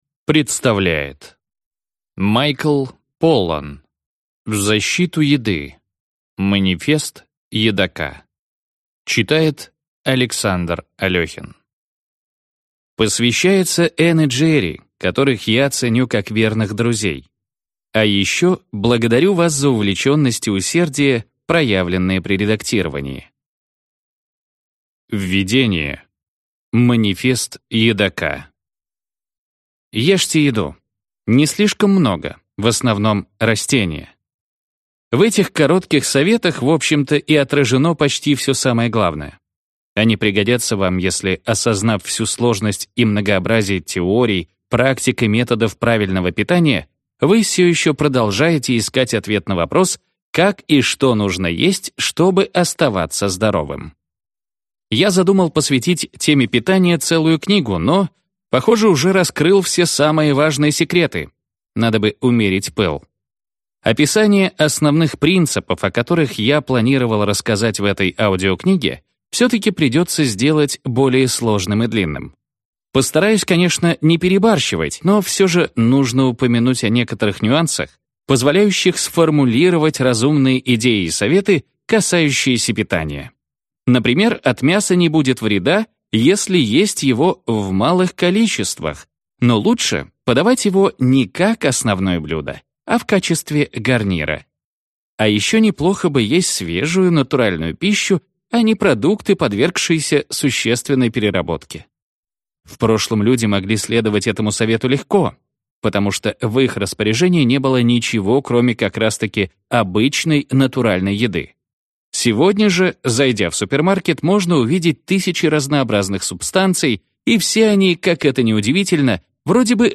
Аудиокнига В защиту еды. Манифест едока | Библиотека аудиокниг